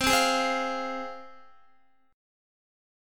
Csus4#5 chord